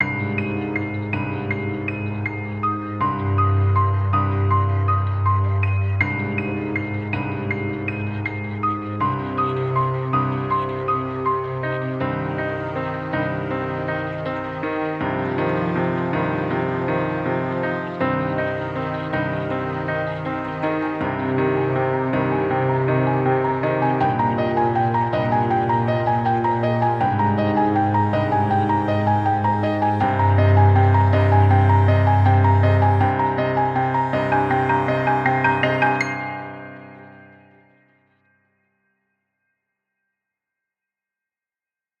Haunting Sequence in G# or Ab
Ambient Atmosphere Background Cinematic Dark Film Flat Halloween sound effect free sound royalty free Movies & TV